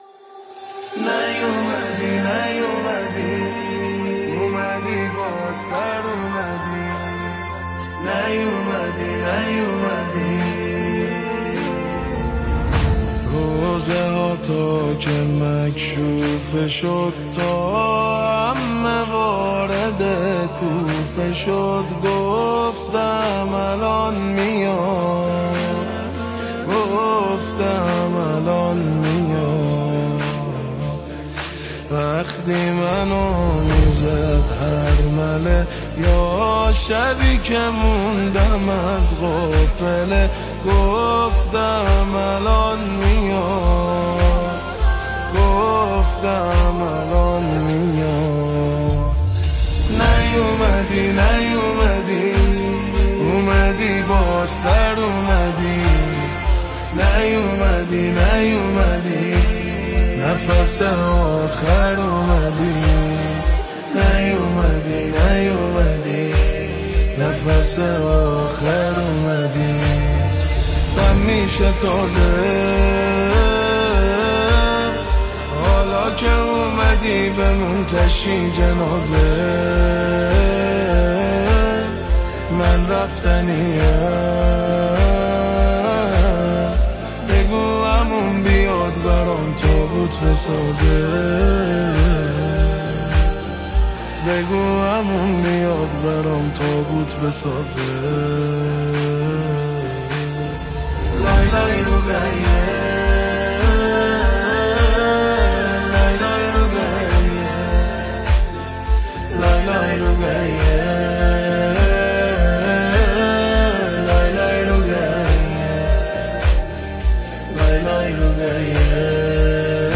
با نوای دلنشین